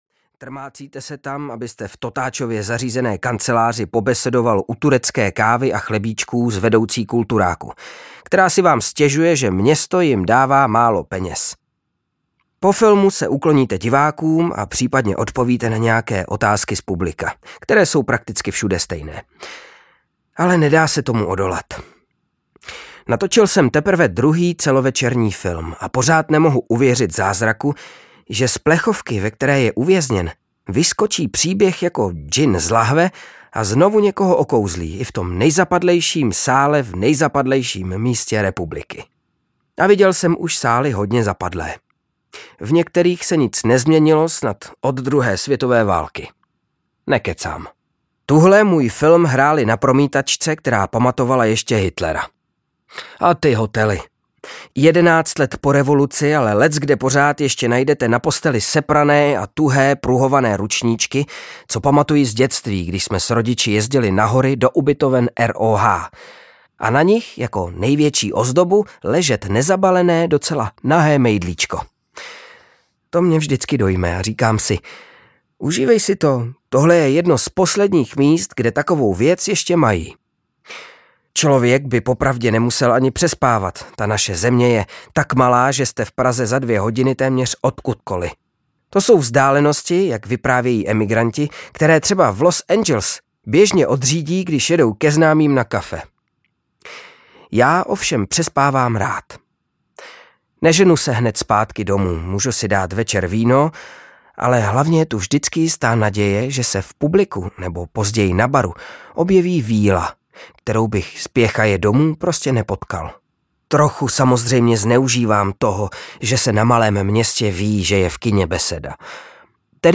Bohemia audiokniha
Ukázka z knihy
• InterpretKryštof Hádek